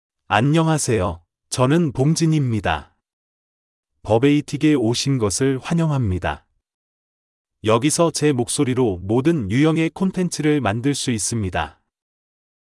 BongJinMale Korean AI voice
BongJin is a male AI voice for Korean (Korea).
Voice sample
Listen to BongJin's male Korean voice.
BongJin delivers clear pronunciation with authentic Korea Korean intonation, making your content sound professionally produced.